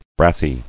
[brass·y]